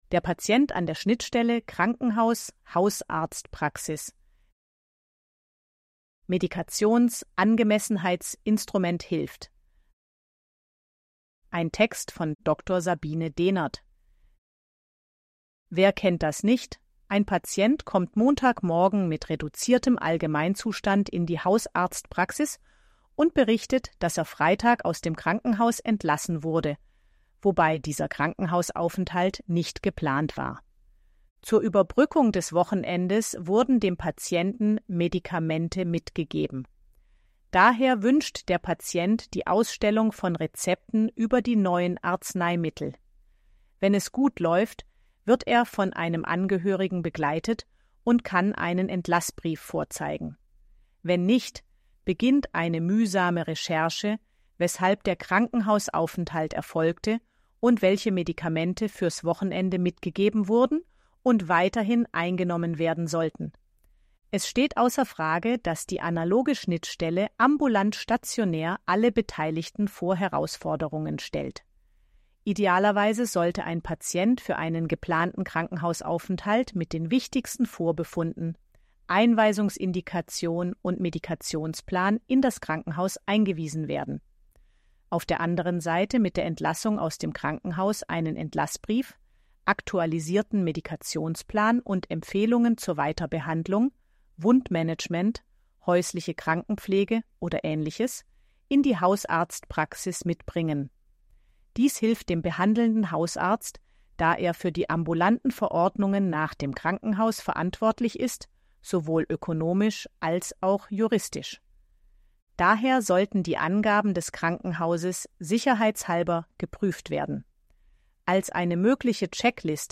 ElevenLabs_KVN261_21_Leonie.mp3